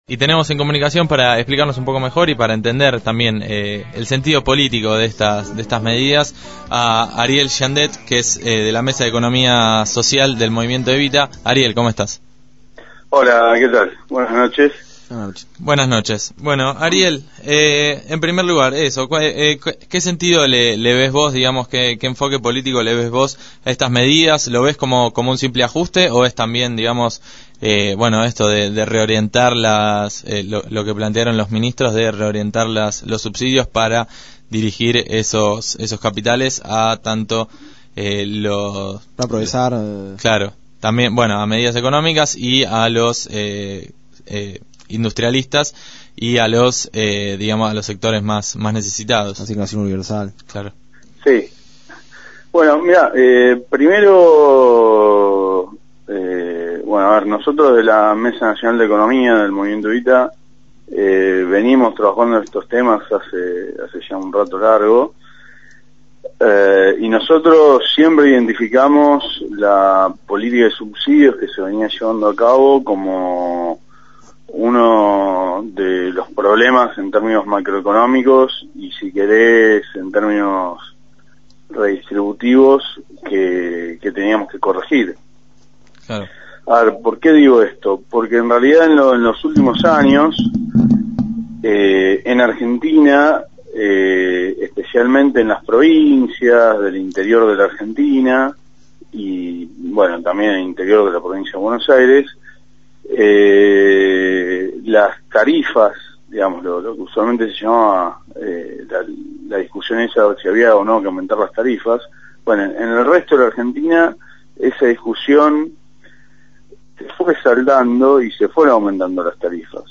en diálogo telefónico